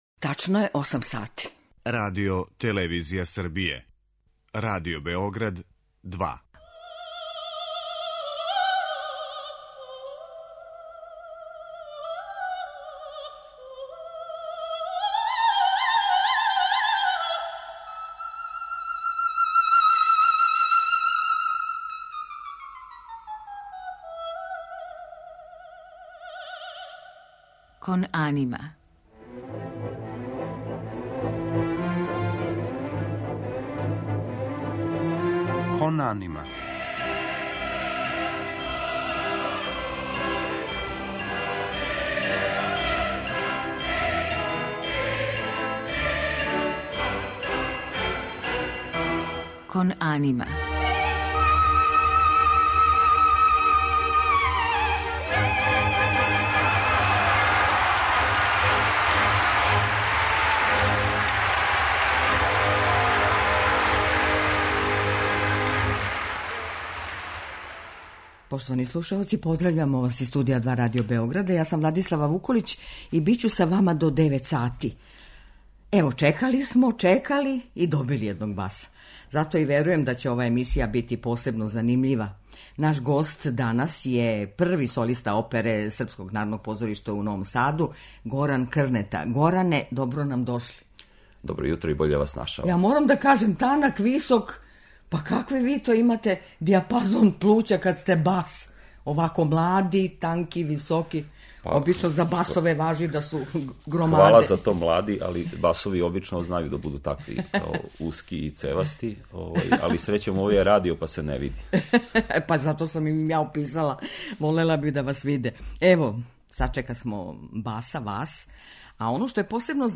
У музичком делу биће емитовани снимци из опера Волфганга Амадеуса Моцарта, Ђузепа Вердија, Петра Иљича Чајковског и Ђоакина Росинија као и фрагменти из Хајдновог ораторијума "Стварање света"